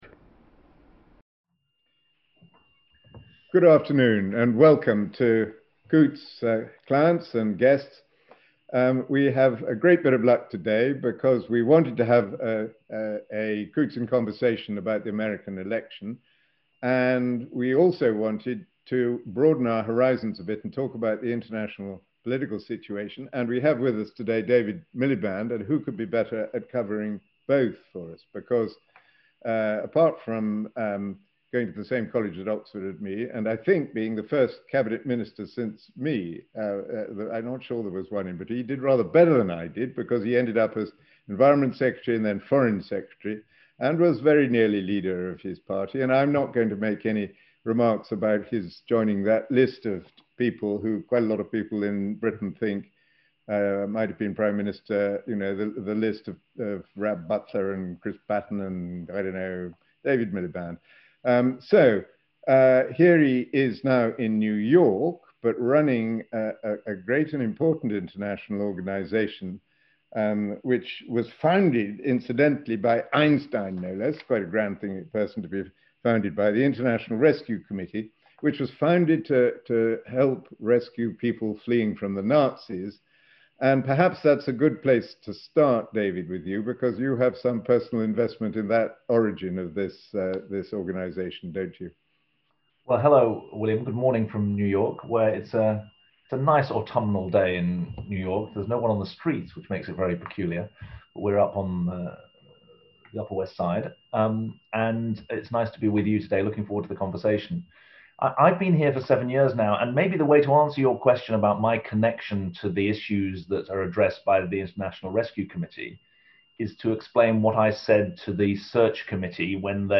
That was one of the messages shared with Coutts clients at a virtual event with David Miliband, President and CEO of the International Rescue Committee (IRC) and former UK Foreign Secretary.